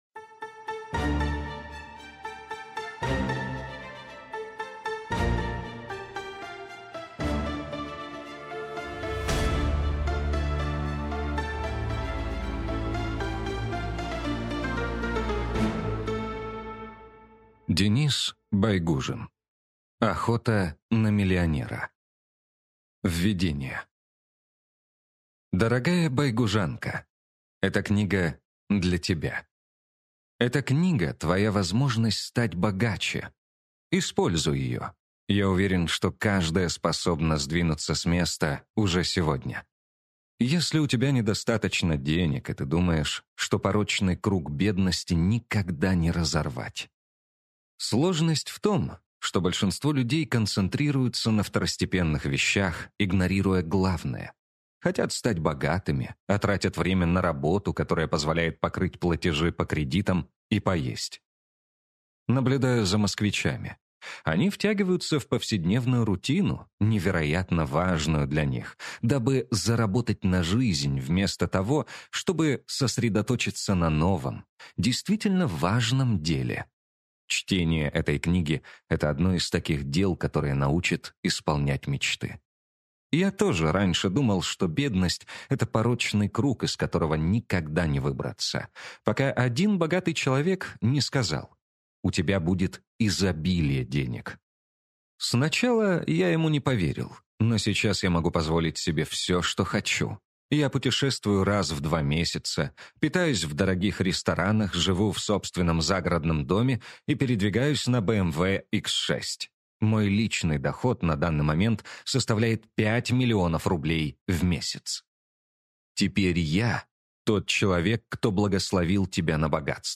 Аудиокнига Охота на миллионера - купить, скачать и слушать онлайн | КнигоПоиск